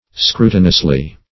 -- Scru"ti*nous*ly , adv.